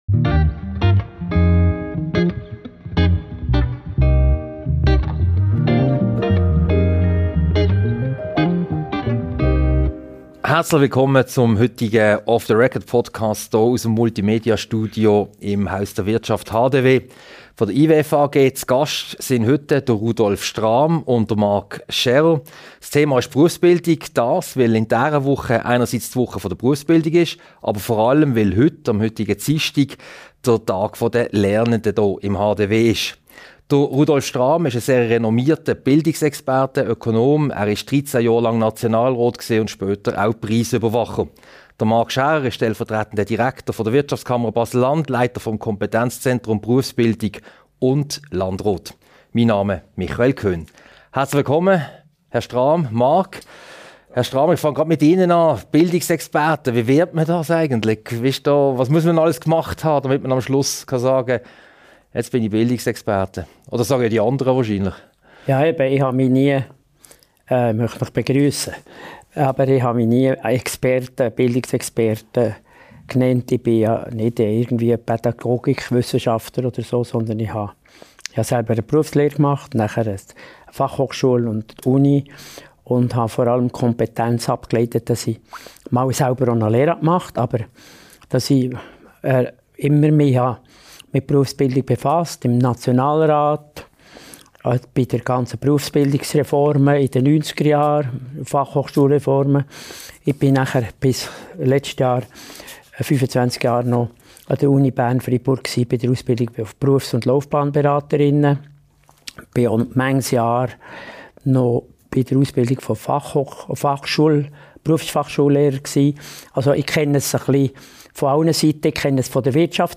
Ein Gespräch über den Stellenwert der Berufsbildung in der Schweiz, die Herausforderungen für den Kanton Baselland sowie das Schweizer Bildungssystem und weshalb die Schweizer Wirtschaft vor allem dank der Berufsbildung funktioniert.